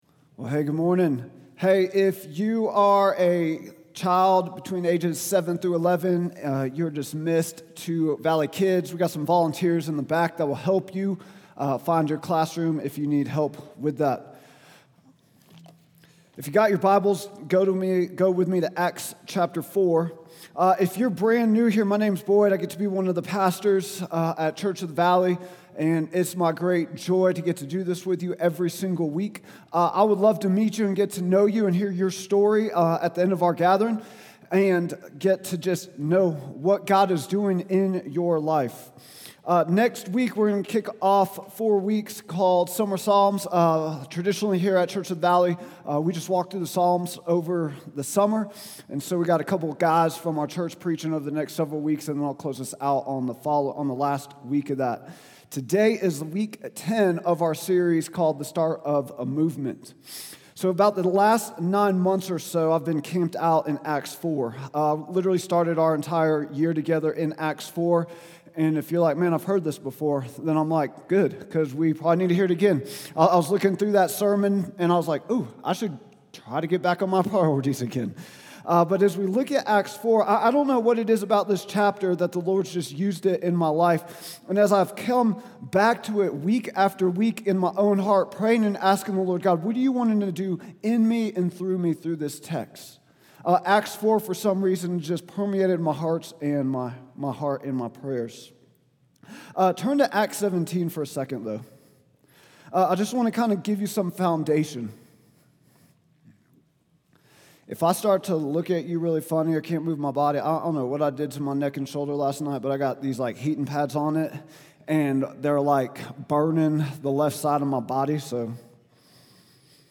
Vision & Values Meet Our Team Statement of Faith Sermons Contact Us Give Start of a Movement | Week 10 June 29, 2025